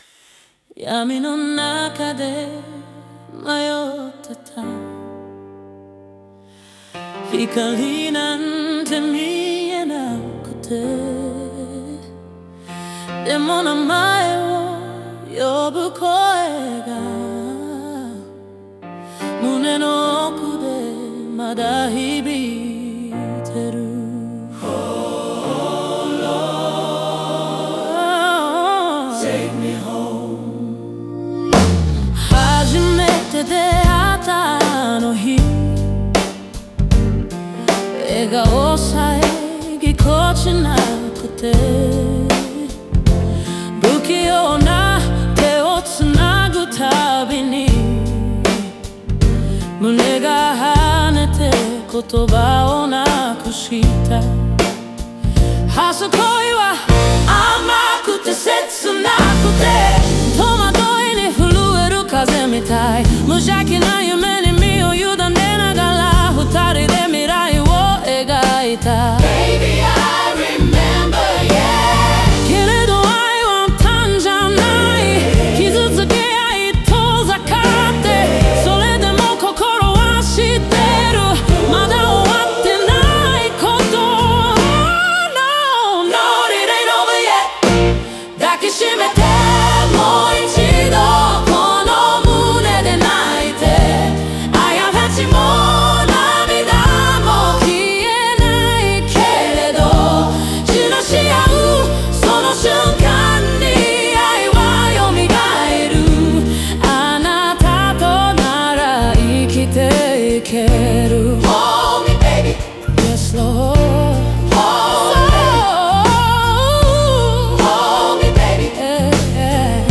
オリジナル曲♪
英語のアドリブを散りばめることでライブ感と情熱を増幅し、リスナーが感情を追体験できる構造になっている。